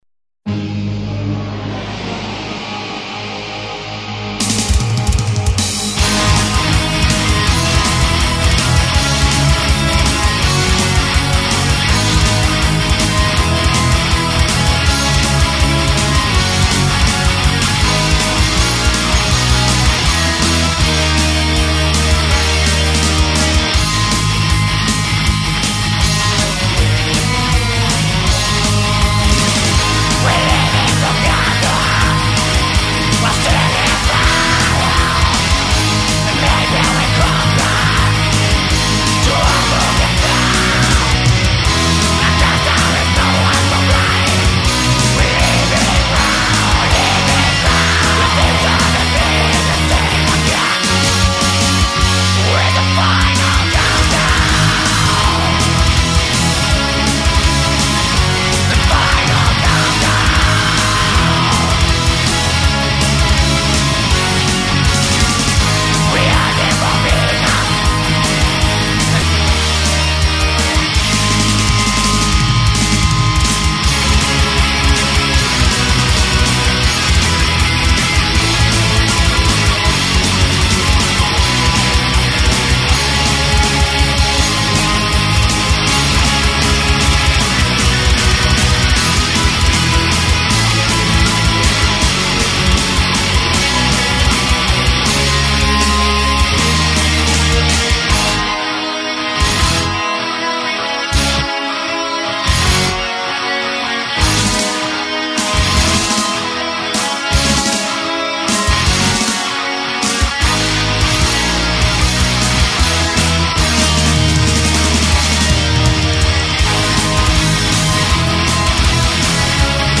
Назад в Музон(metall)